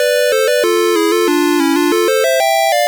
Source Recorded from the Sharp X1 version.